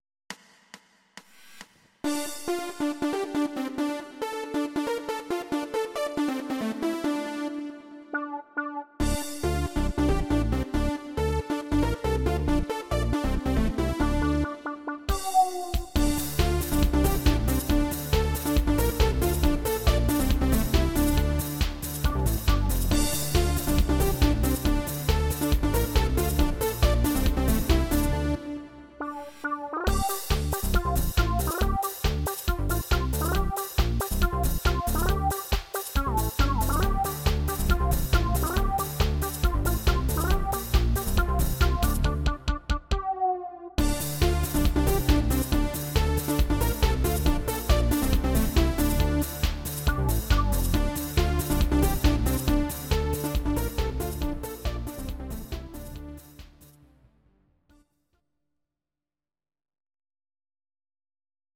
Audio Recordings based on Midi-files
Pop, Instrumental